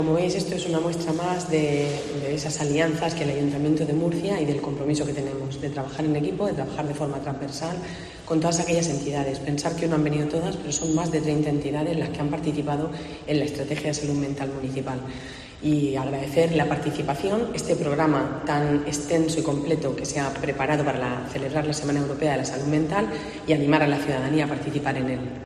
Pilar Torres, concejala de Salud